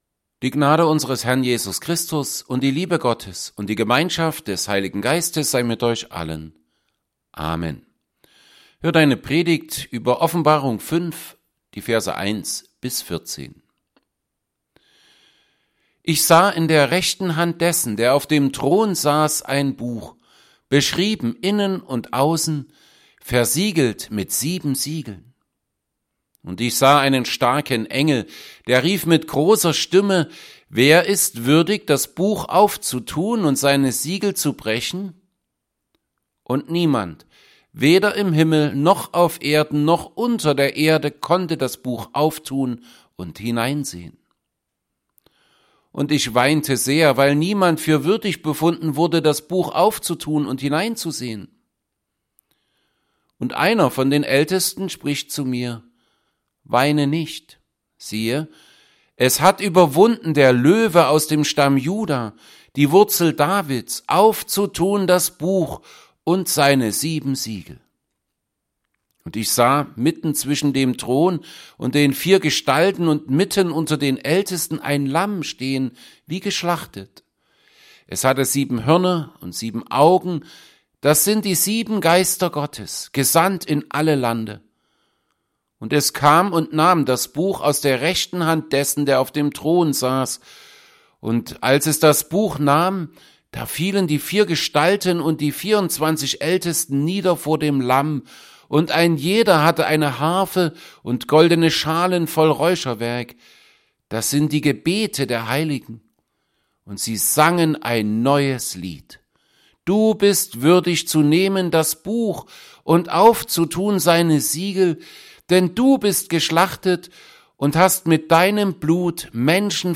Predigt_zu_Offenbarung_5_1b14.mp3